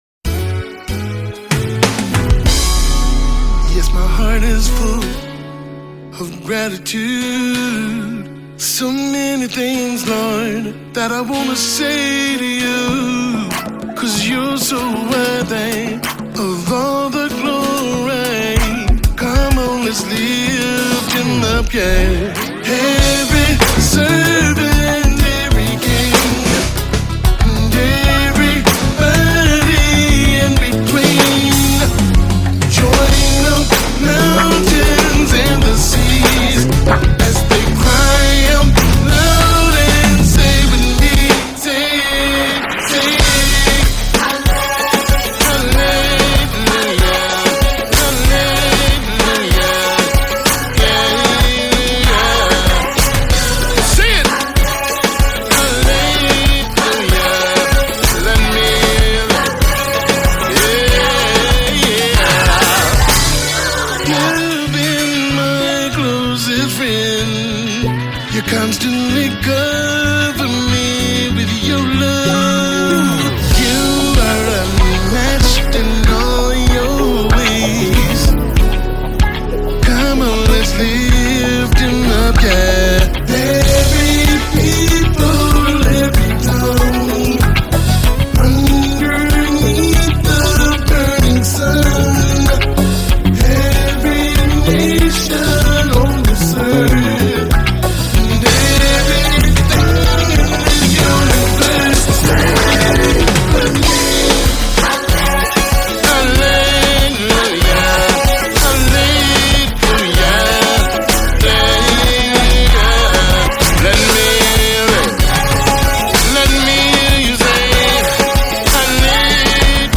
Foreign Artists - Gospel Songs Collection
vocal delivery is marked by sincerity and passion